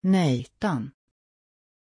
Pronunciation of Nathan
pronunciation-nathan-sv.mp3